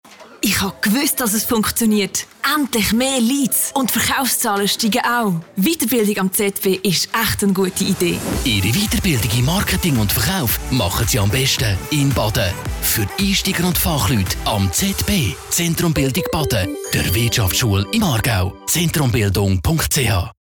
Werbung Schweizerdeutsch (AG)
Sprecherin mit breitem Einsatzspektrum.